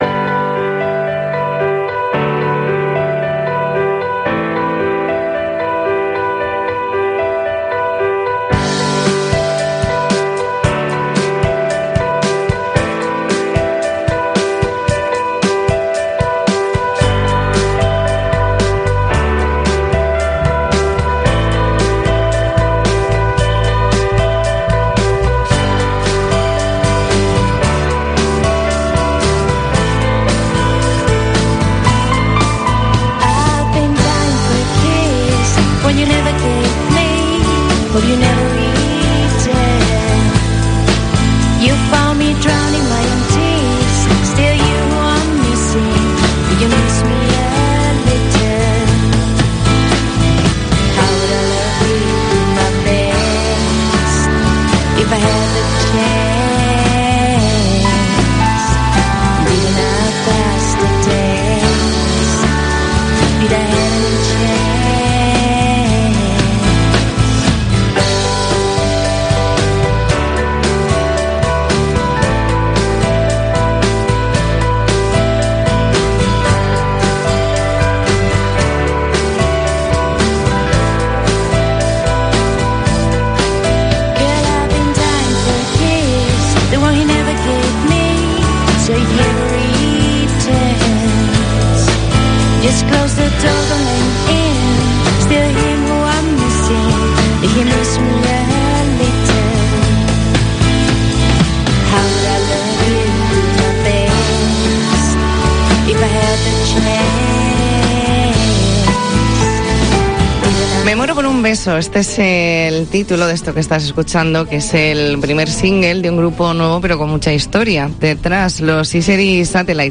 E ntrevista en La Mañana en COPE Más Mallorca, jueves 23 de junio de 2022.